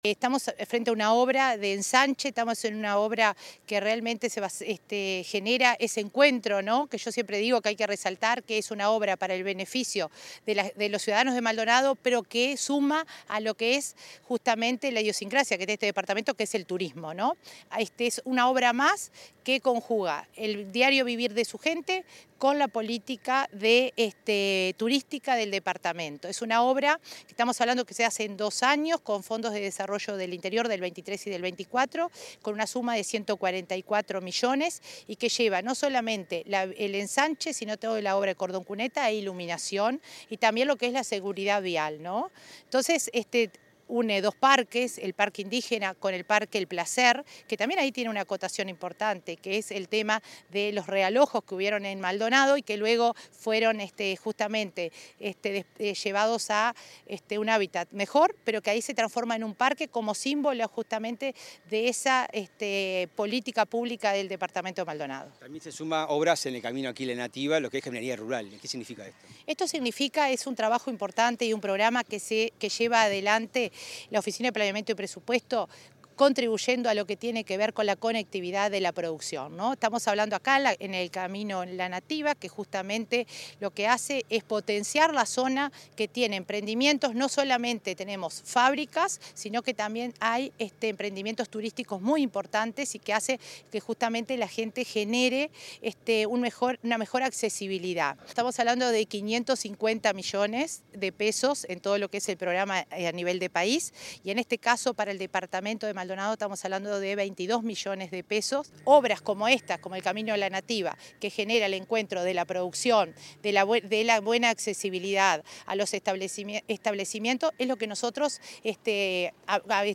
Entrevista a la coordinadora de la OPP, María de Lima
La Oficina de Planeamiento y Presupuesto (OPP) y la Intendencia de Maldonado trabajan en la mejora de infraestructura para facilitar el acceso a emprendimientos turísticos, la producción agrícola y ganadera, así como para promover la conexión entre la ciudad y el campo. La coordinadora de Descentralización y Cohesión de la OPP, María de Lima, en entrevista con Comunicación Presidencial, destacó las obras.